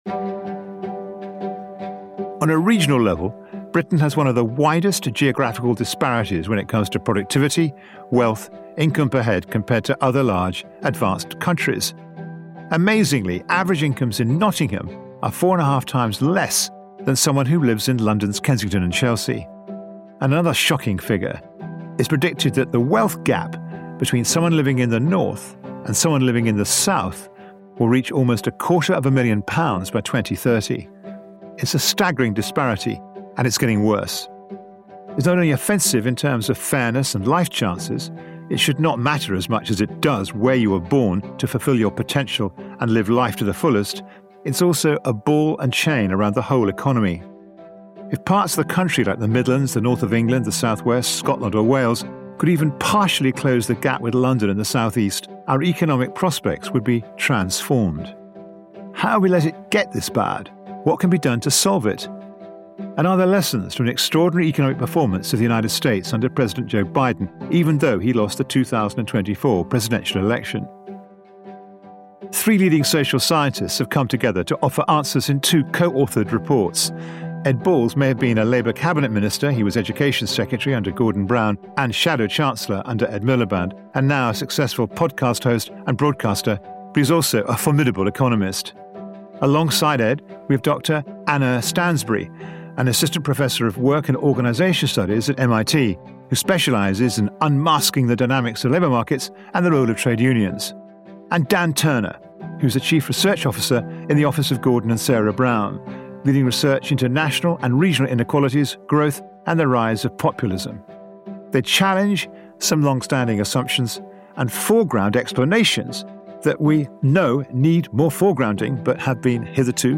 In the final episode of Season 8 of the We Society, our host Will Hutton is joined by economist and former Labour politician Ed Balls